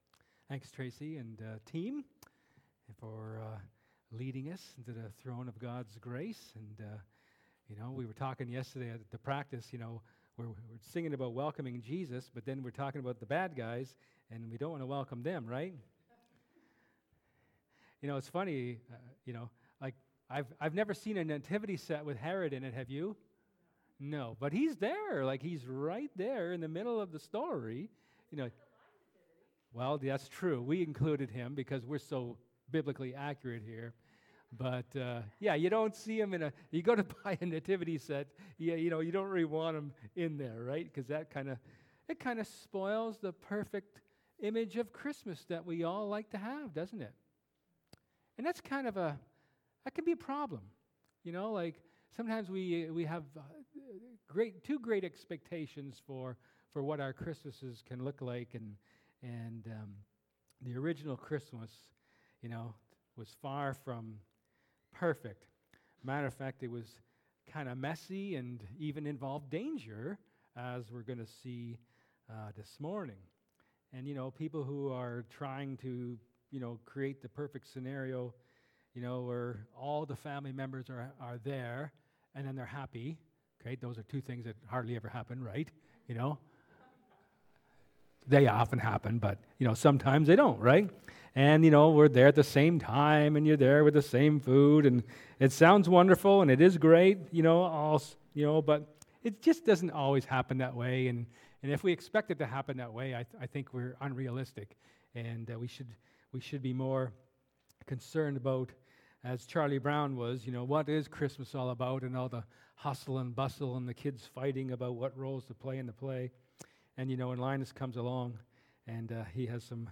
Luke 2:8-20 Service Type: Sermon